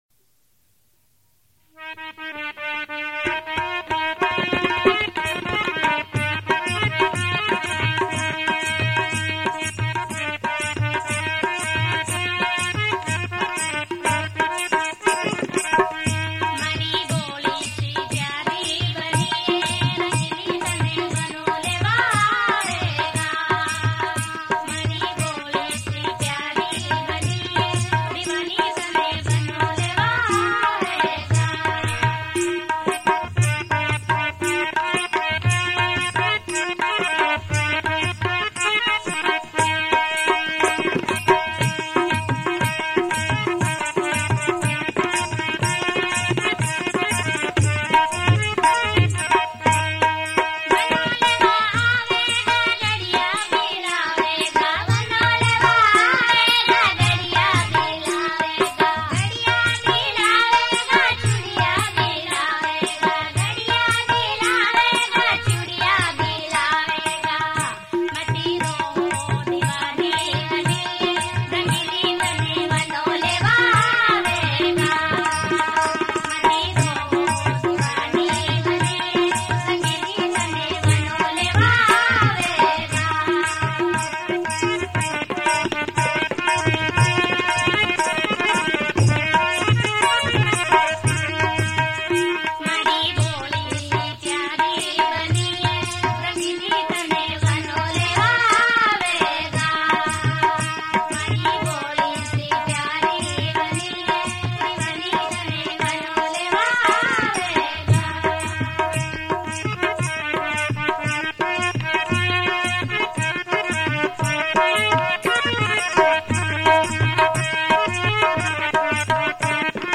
Rajasthani Songs
Banna Banni Geet